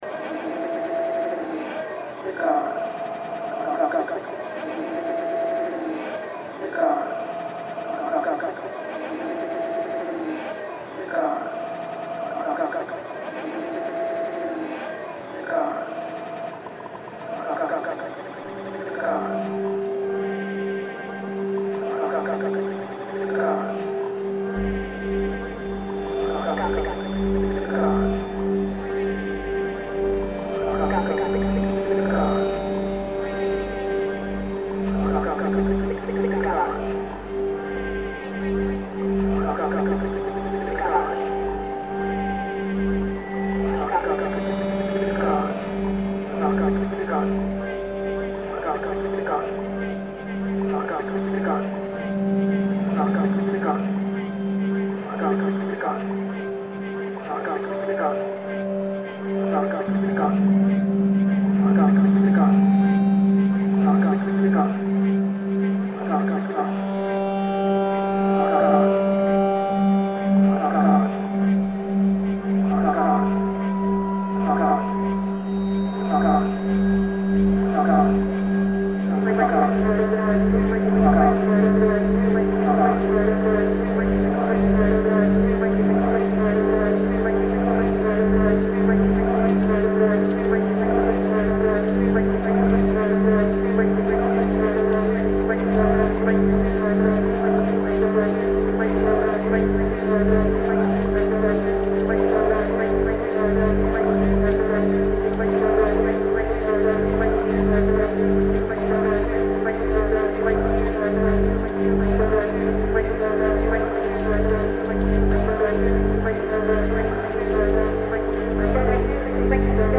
In RGB (105,105,105), the audio source from an Oxford race event was deconstructed and then recomposed in loops, so as to embody the enigma of DimGrey. Drone and ambient sounds were incorporated into the track to accentuate the DimGrey texture.